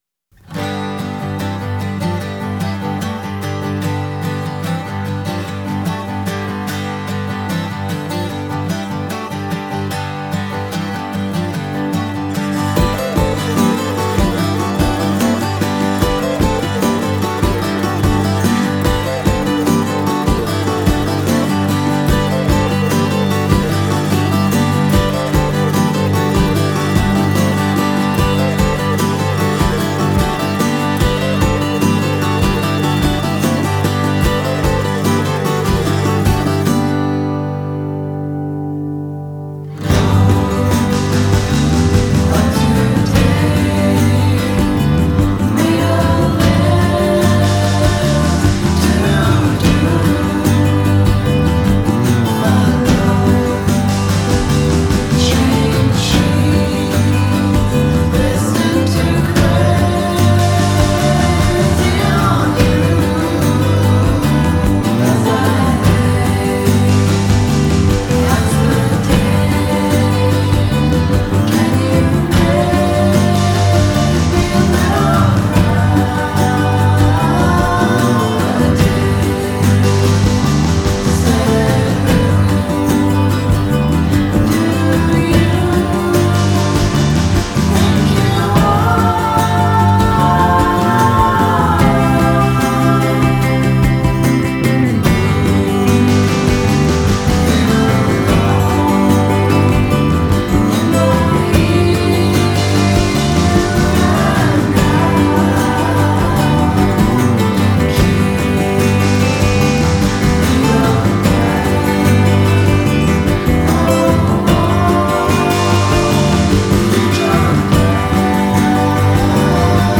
a bitchin’ psych-rock outfit from San Francisco.